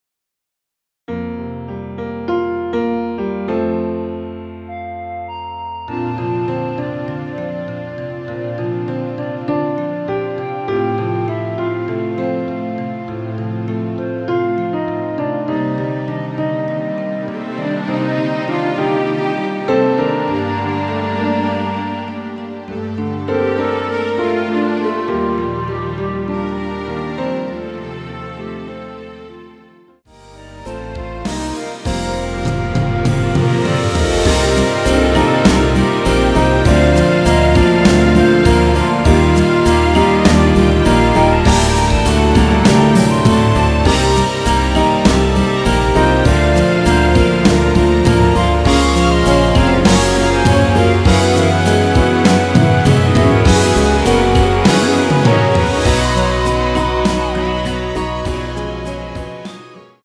전주 없이 시작 하는 곡이라 전주 만들어 놓았습니다.(미리듣기참조)
원키에서(-2)내린 멜로디 포함된 MR입니다.
Bb
앞부분30초, 뒷부분30초씩 편집해서 올려 드리고 있습니다.
중간에 음이 끈어지고 다시 나오는 이유는